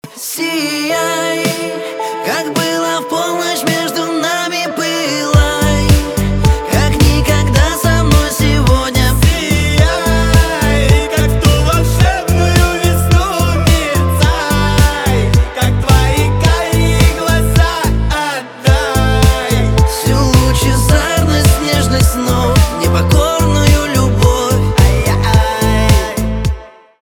поп
романтические , чувственные